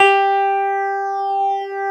Index of /90_sSampleCDs/USB Soundscan vol.09 - Keyboards Old School [AKAI] 1CD/Partition B/04-CLAVINET3
CLAVI3  G4.wav